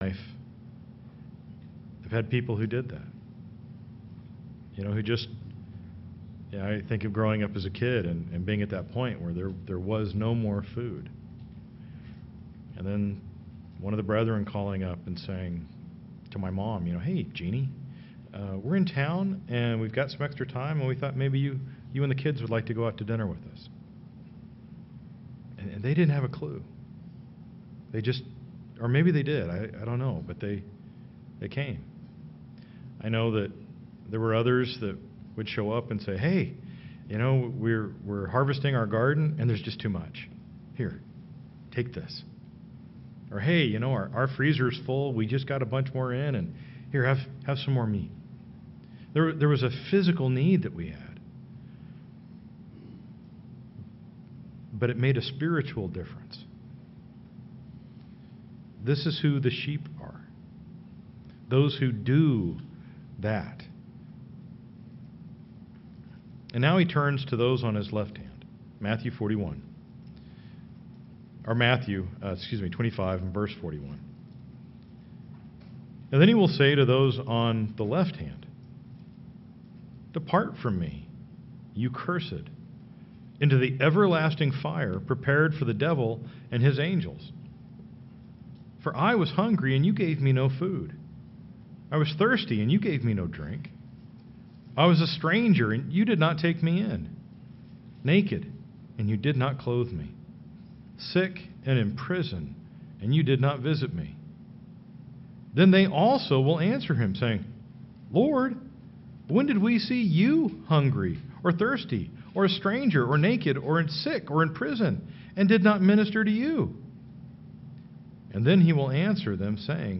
We should strive to be more like sheep through studying God's word, doing what is good, not being contentious and being aware when we are led astray by goats. NOTE: This is a partial recording due to technical issues